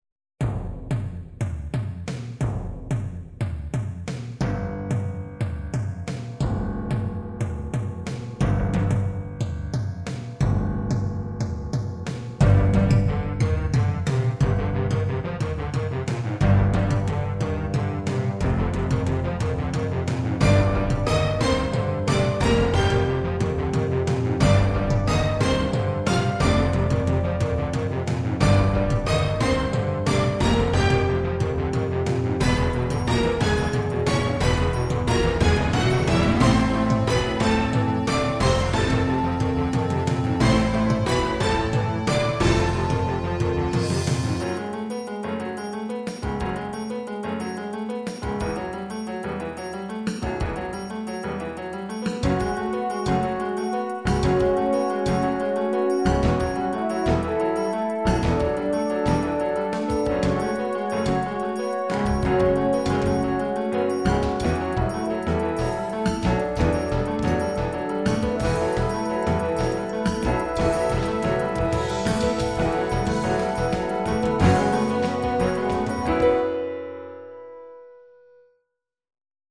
Sintonía para juego de ordenador acción ARCADE